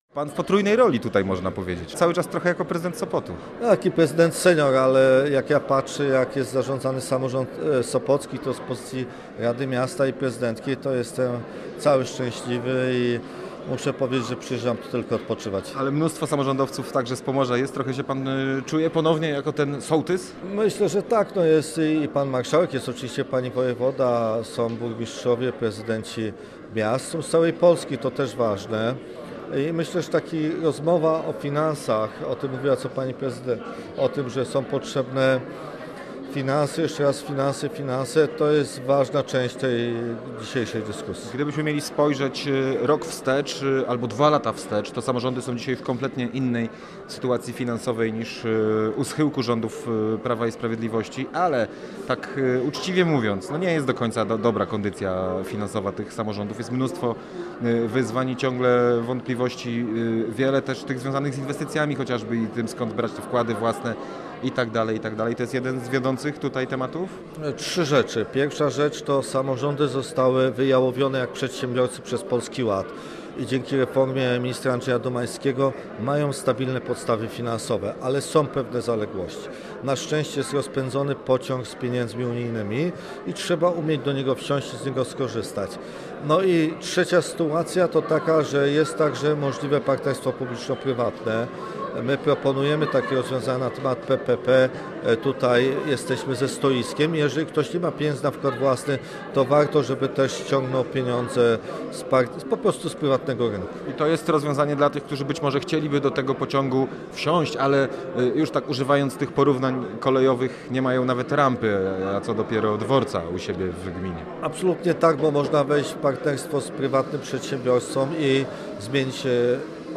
Wśród uczestników jest Jacek Karnowski, wiceminister funduszy i polityki regionalnej, były wieloletni prezydent Sopotu. W rozmowie z dziennikarzem Radia Gdańsk odniósł się do kondycji samorządów po okresie rządów Prawa i Sprawiedliwości.